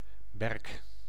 Ääntäminen
Synonyymit bouleau blanc Ääntäminen France: IPA: /bu.lo/ Haettu sana löytyi näillä lähdekielillä: ranska Käännös Ääninäyte Substantiivit 1. berk {m} 2. berkeboom Suku: m .